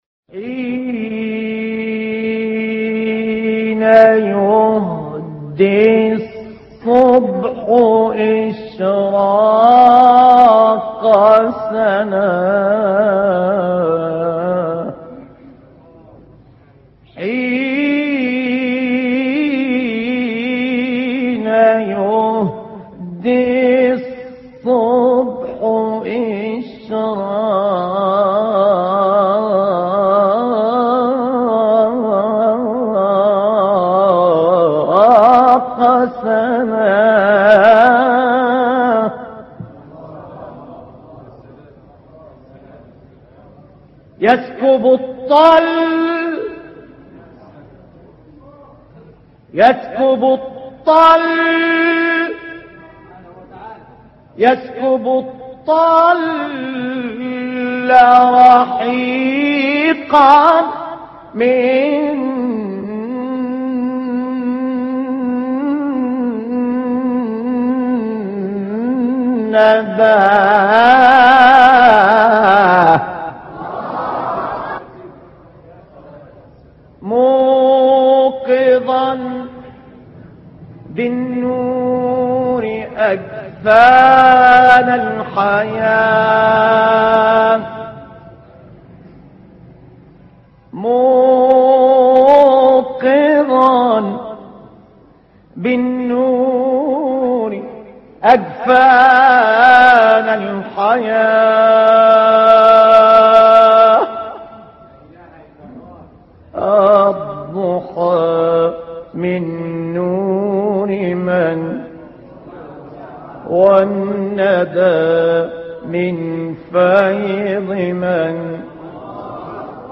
ابتهال نوروزی/ ۵